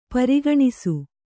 ♪ pari gṇisu